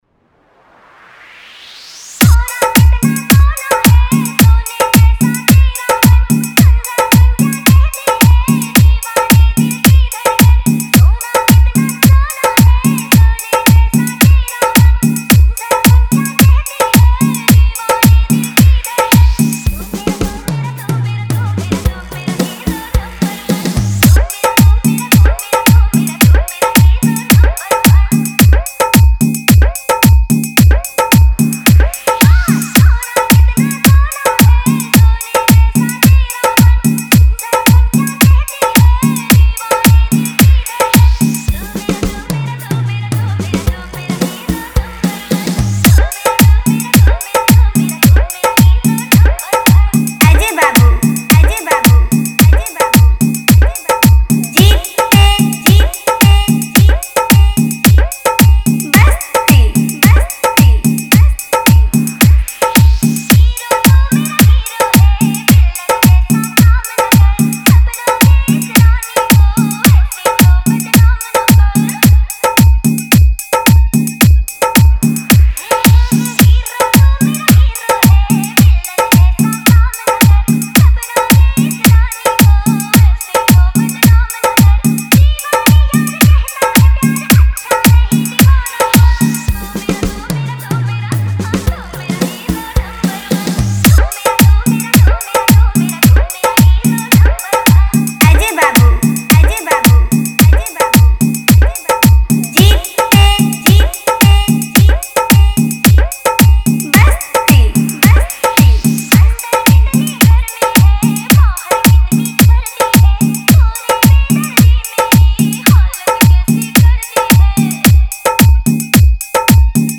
Category : Hindi Wala Dj Remix